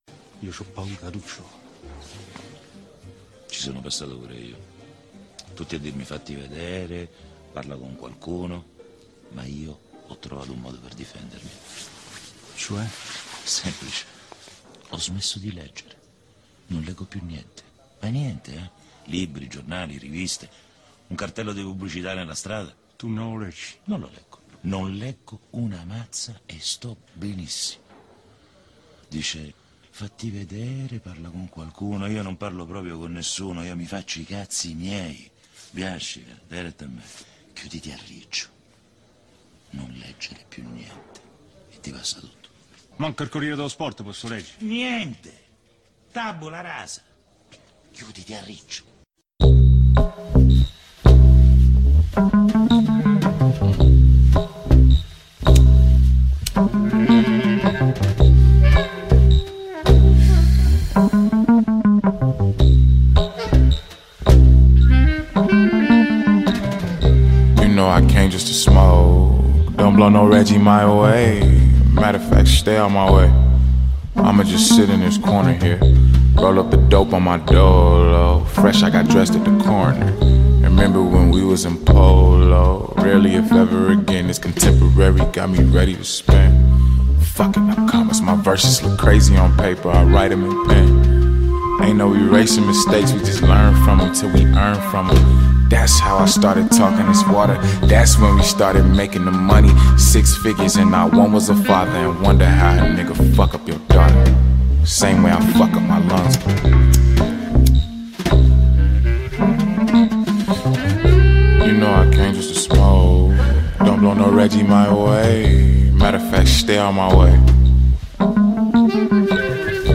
La trasmissione che legge i libri per voi.